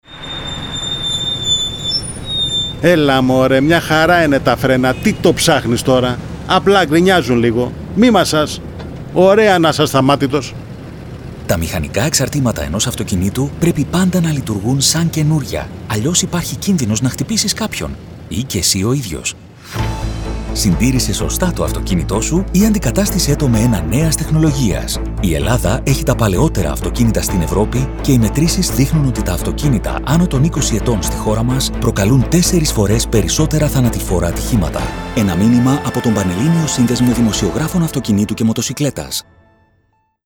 Ραδιοφωνικά μηνύματα σχετικά με τη δημόσια υγεία, την οδική ασφάλεια και την εξυπηρέτηση της κυκλοφορίας στα αστικά δίκτυα, μεταδίδονται από αρχές Ιουλίου (προς το παρόν σε περιορισμένο δίκτυο ραδιοφωνικών σταθμών του Λεκανοπεδίου).